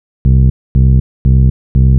TSNRG2 Off Bass 001.wav